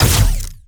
Plasmid Machinegun
GUNAuto_Plasmid Machinegun Single_06_SFRMS_SCIWPNS.wav